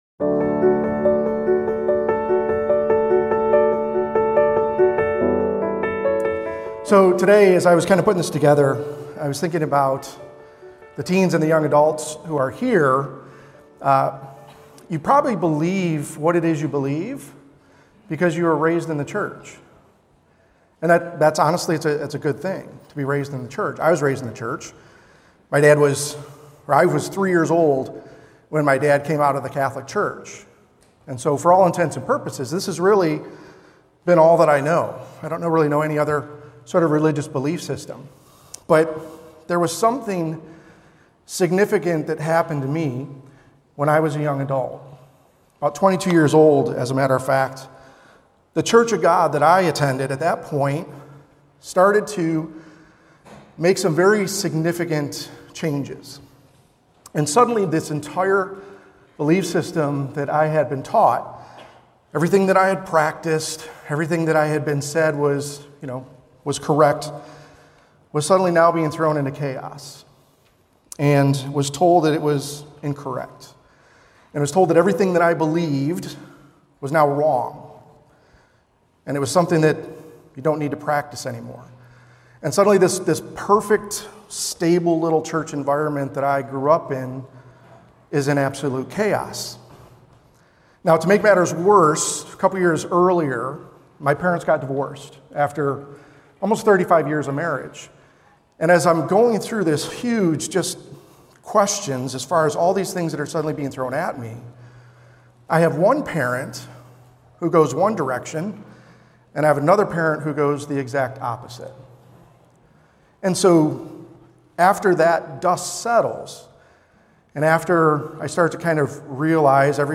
Winter Family Weekend Seminar